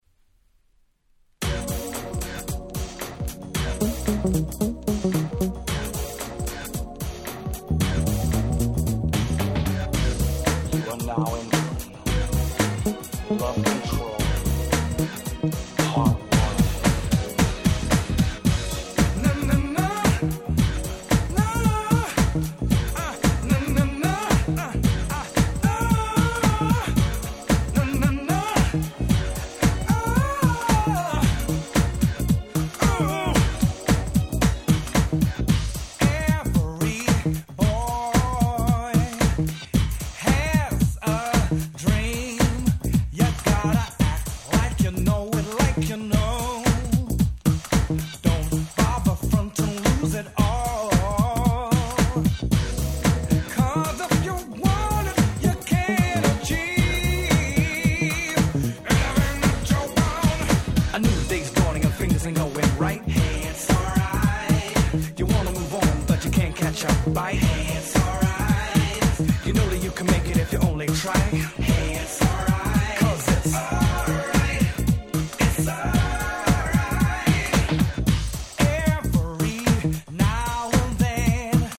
93' Nice UK R&B !!